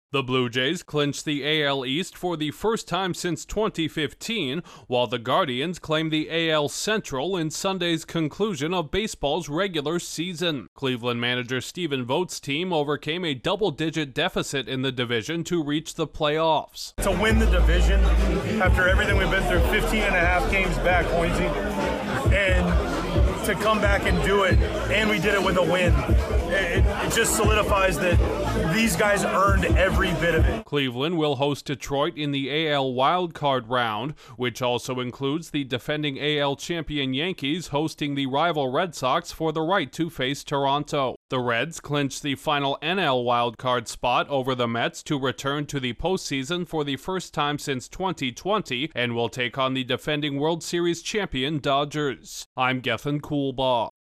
Baseball’s postseason picture is set after an action-packed final day. Correspondent